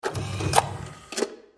دانلود صدای ربات 30 از ساعد نیوز با لینک مستقیم و کیفیت بالا
جلوه های صوتی